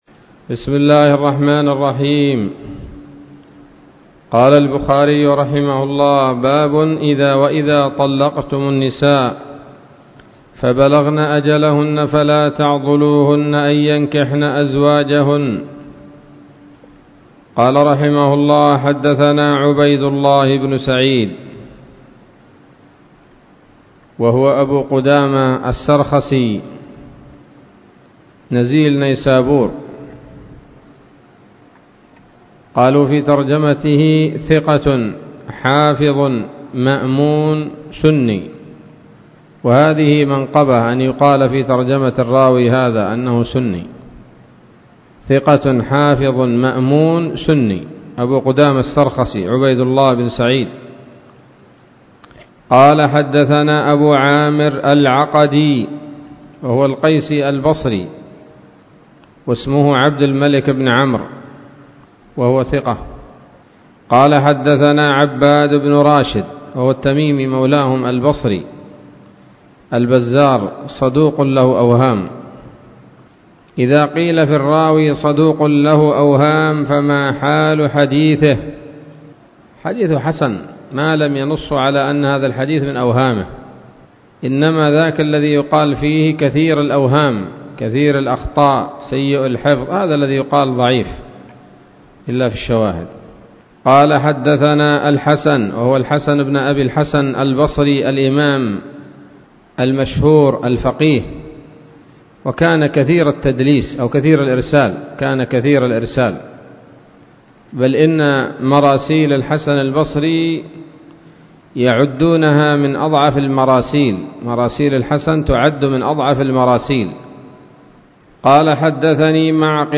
الدرس الثالث والثلاثون من كتاب التفسير من صحيح الإمام البخاري